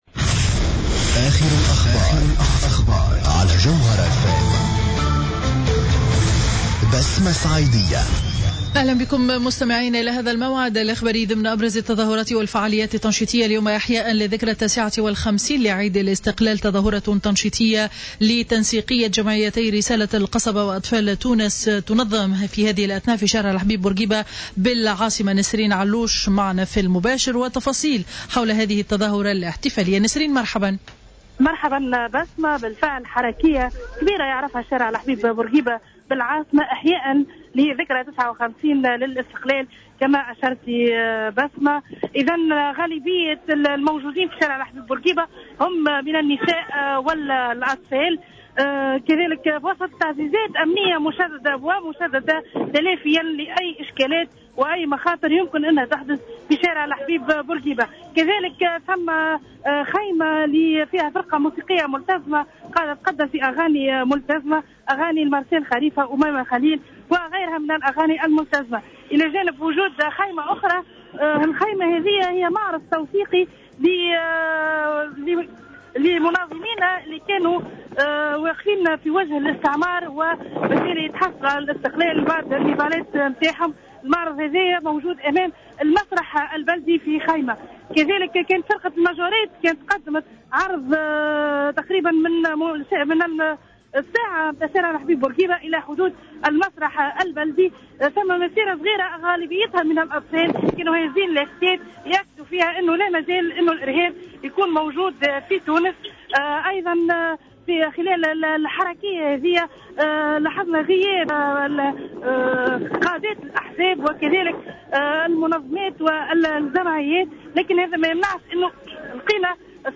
نشرة أخبار منتصف النهار ليوم الجمعة 20 مارس 2015